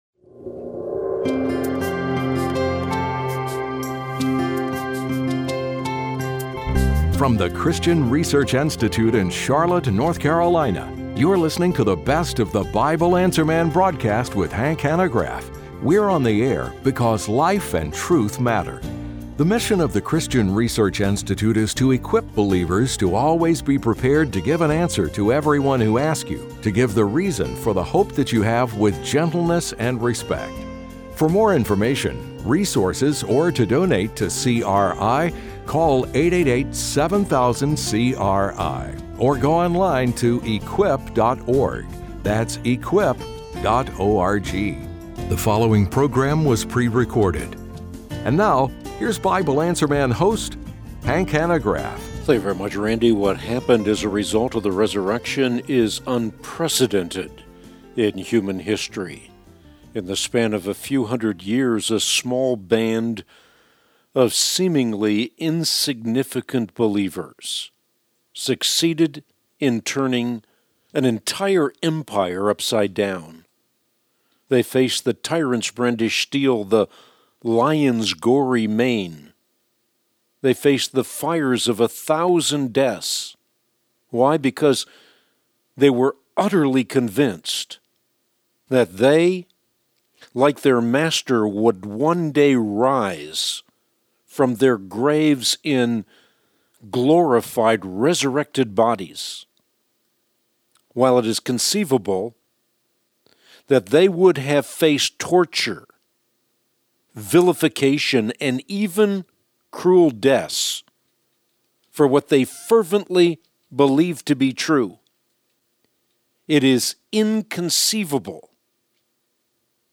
Best of BAM: The Result of the Resurrection, and Q&A | Christian Research Institute